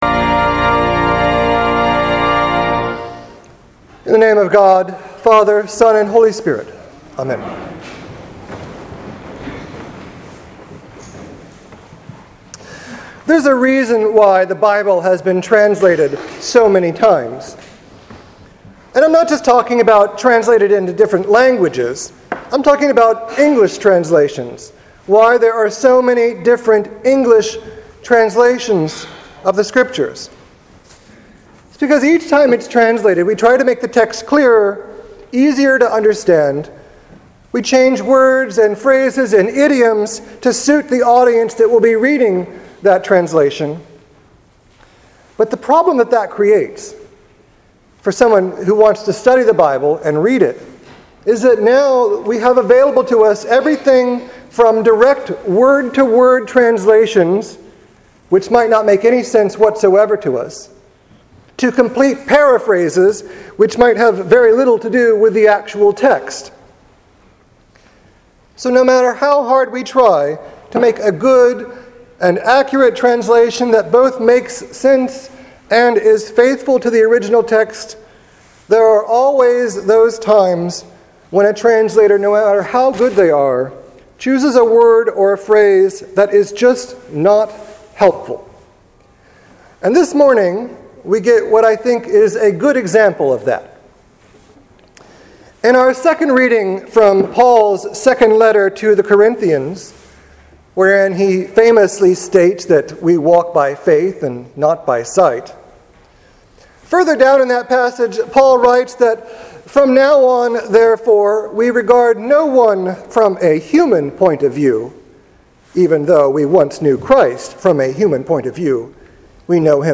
Knowing that we don’t know: Walking by faith and not by sight. Sermon for June 14th, 2015.